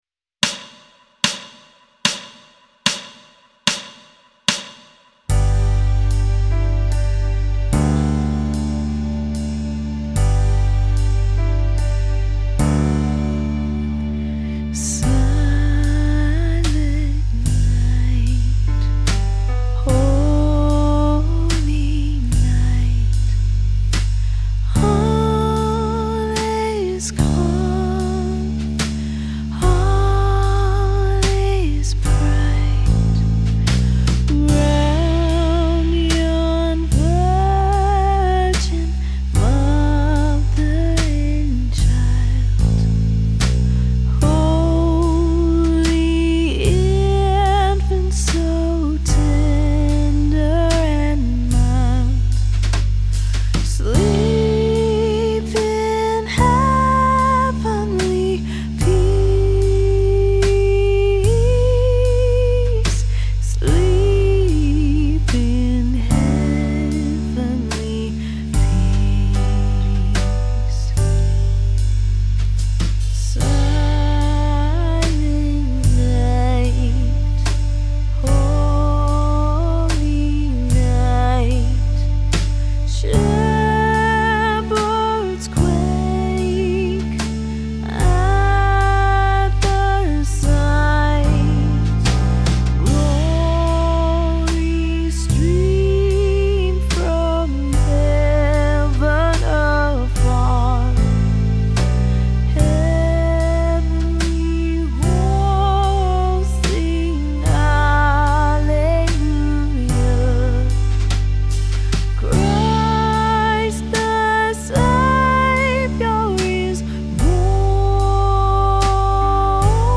Jam Track No Vocals
"Silent Night" Back to Songs Page Download the PDF Jam Track No Vocals Due to copyright law, downloadable jam tracks are instrumental only.
The song uses only three chords, and I’ve included multiple strumming and fingerstyle options so you can choose the approach that fits your current level and style. You’ll find two jam tracks: 1 Guitar removed — you step in as the guitarist. 2 Guitar and vocals removed — so you can take on both roles if you want.